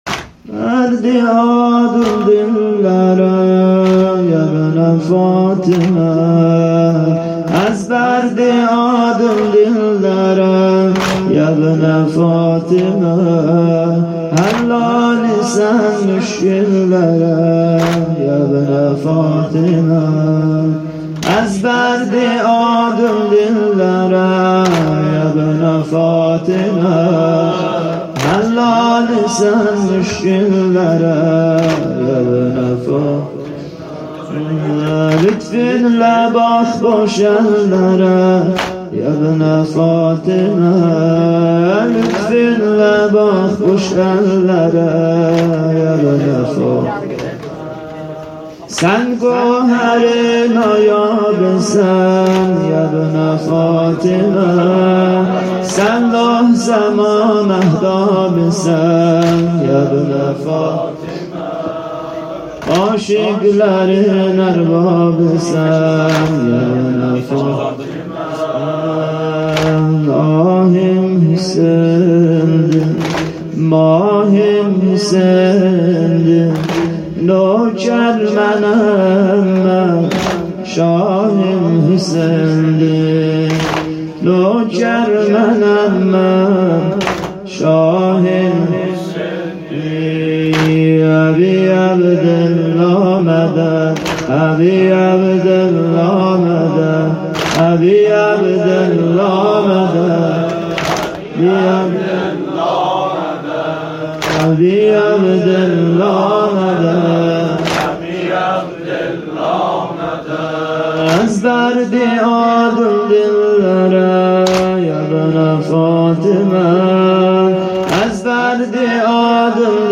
هیات هفتگی
بخش دوم سینه زنی